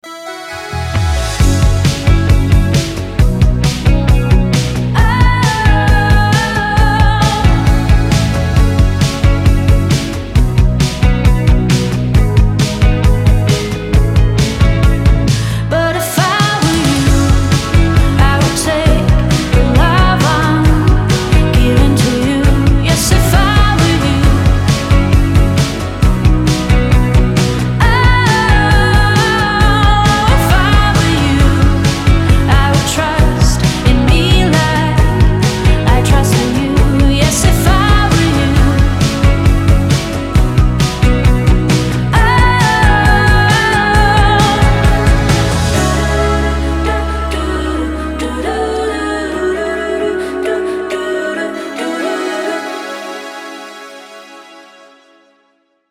• Качество: 320, Stereo
поп
спокойные
нежные